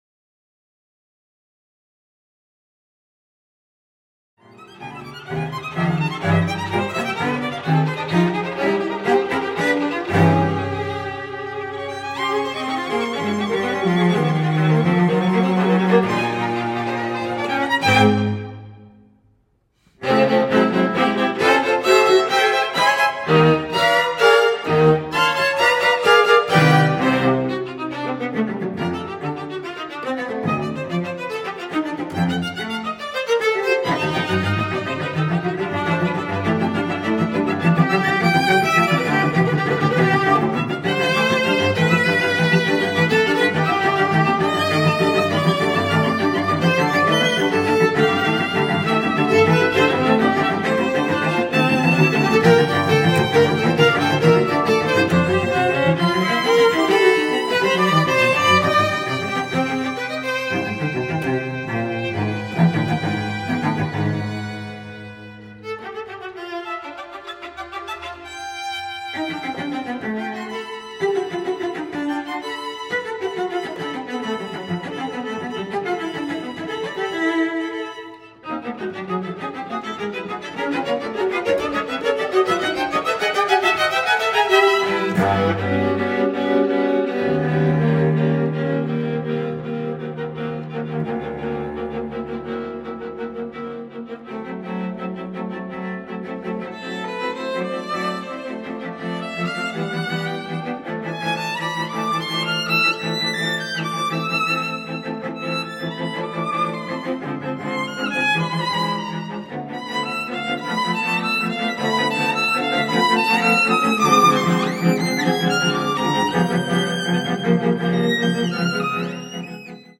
Allegro preciso e agitato